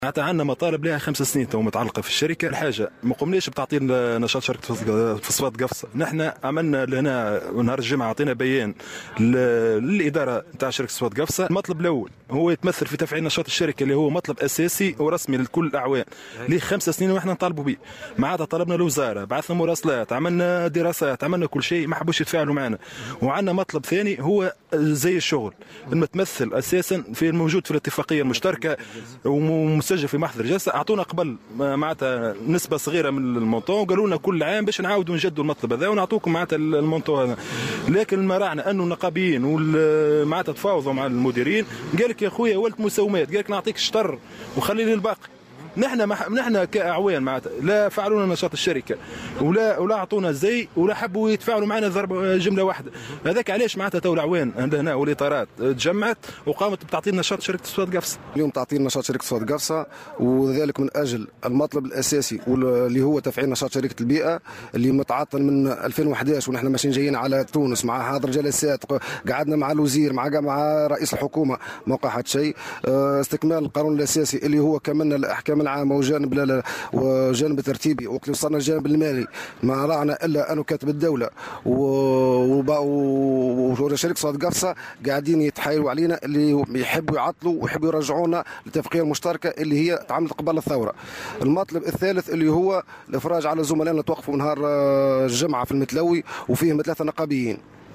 Les protestataires ont procédé à la suspension des activités du district de la CPG, pour réclamer le démarrage des activités de la société de l'environnement, une réclamation qui persiste depuis plus de cinq ans, a indiqué l'un des protestataires au micro du correspondant de Jawhara FM.